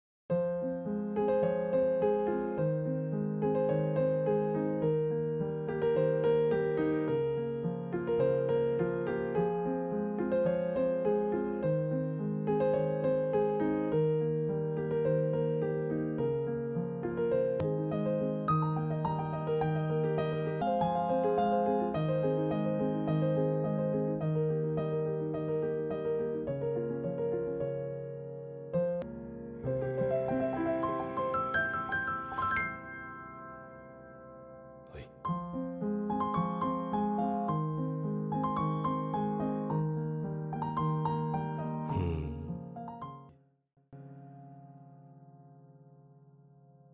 Méditation musicale :